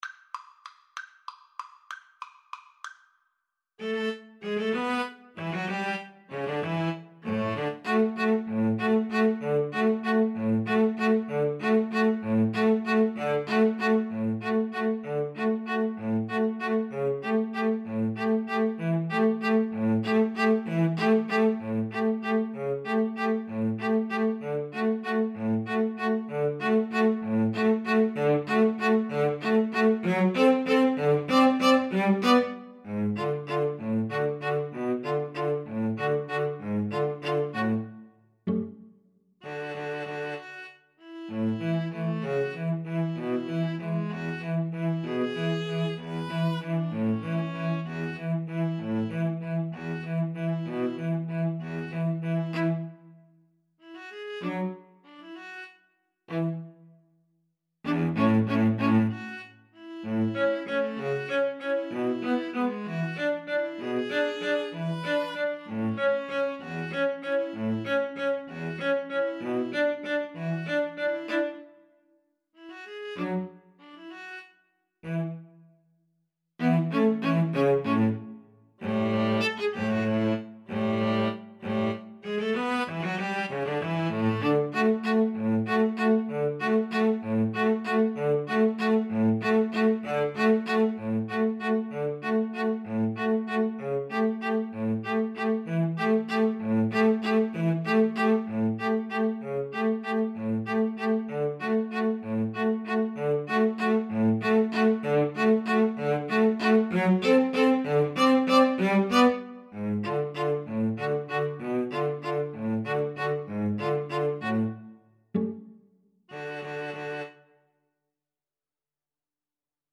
Tempo di Waltz (.=c.64)
Classical (View more Classical String trio Music)